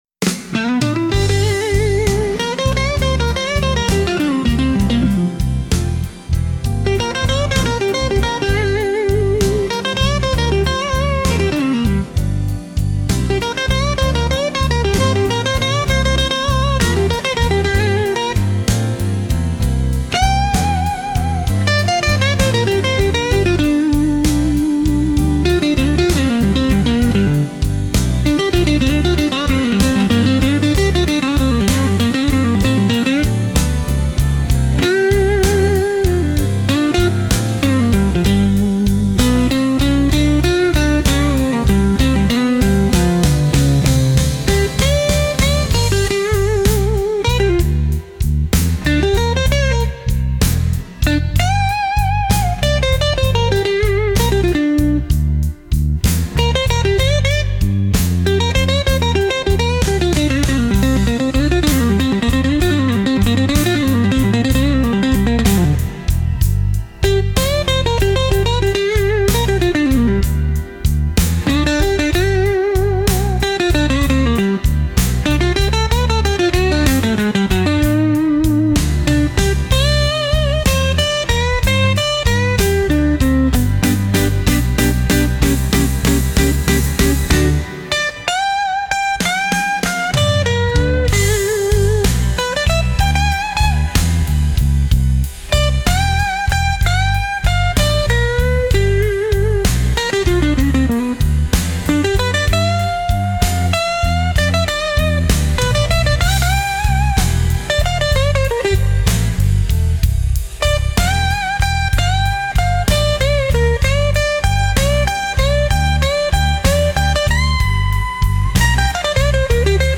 Instrumental - RLMradio Dot XYZ - 4. min.mp3 - Grimnir Radio